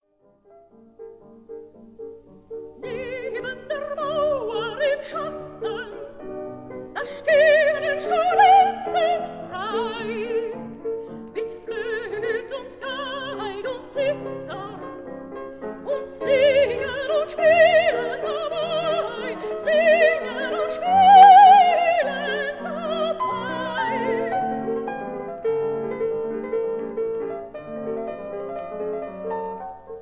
soprano
piano
Sofiensaal, Vienna